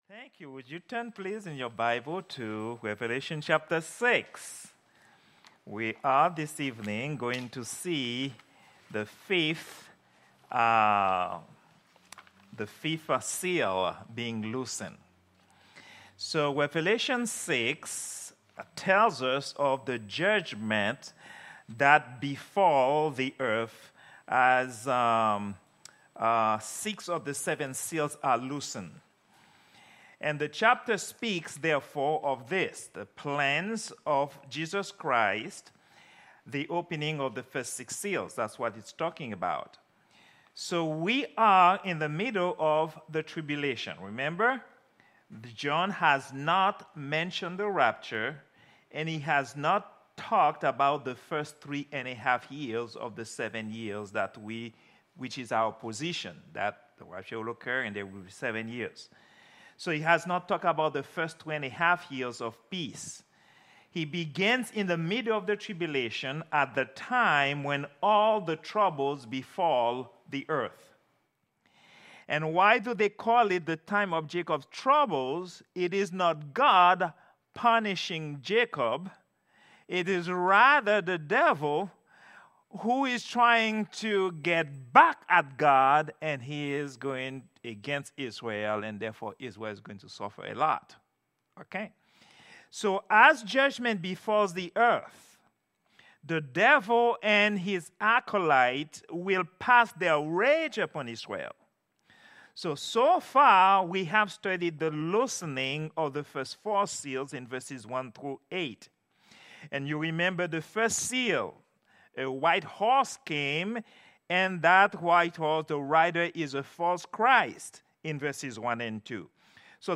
Prayer_Meeting_06_12_2024.mp3